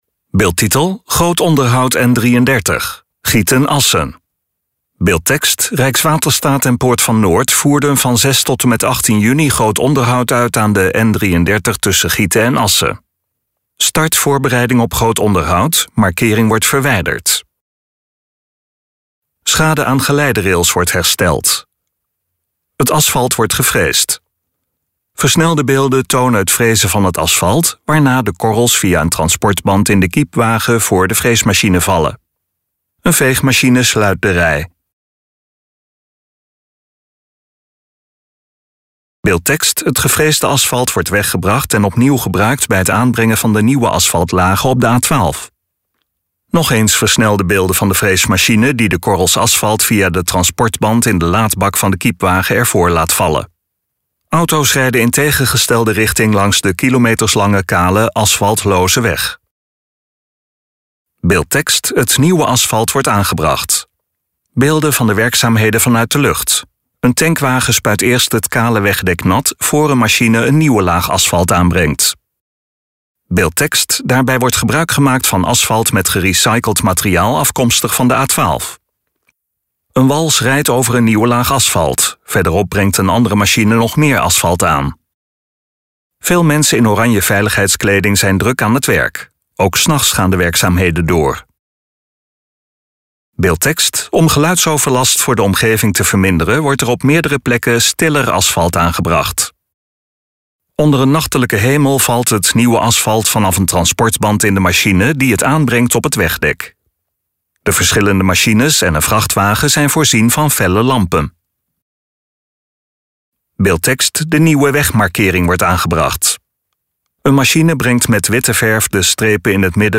LEVENDIGE MUZIEK (Schade aan geleiderails wordt hersteld.